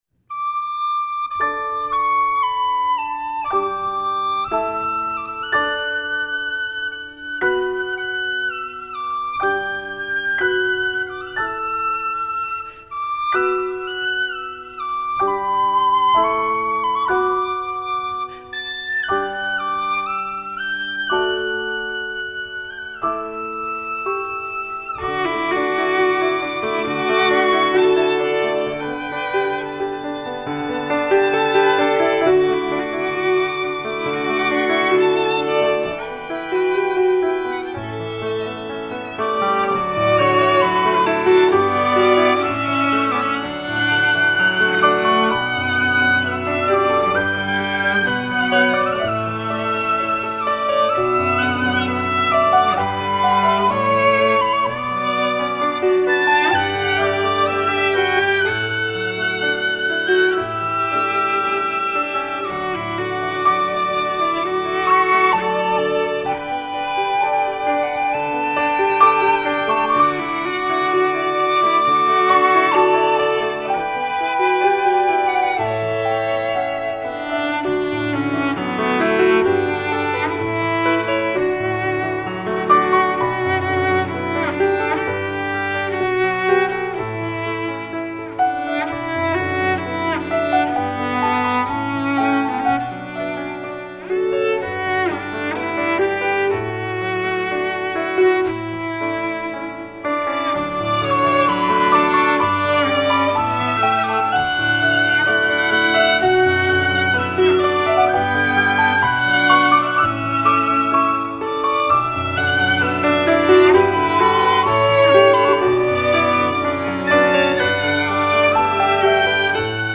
Instrumentation: Flute, Violin, Cello, Piano